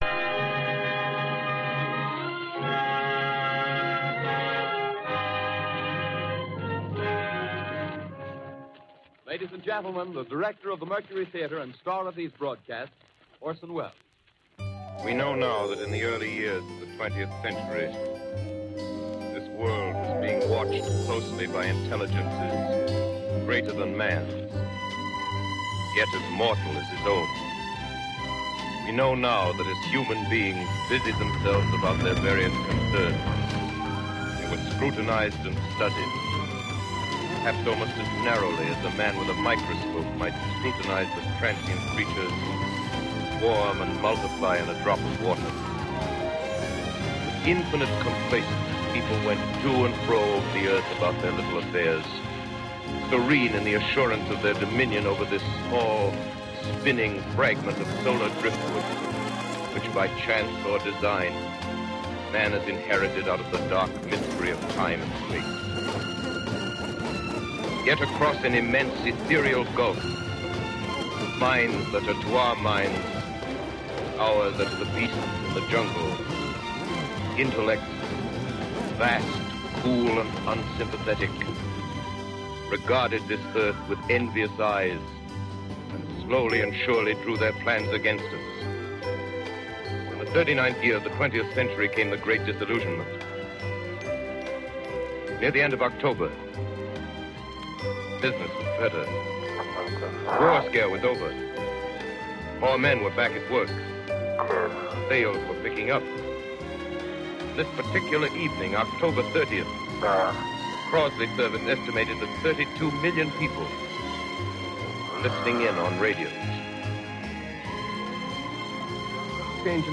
Annual Halloween broadcast of Orson Welles' radio...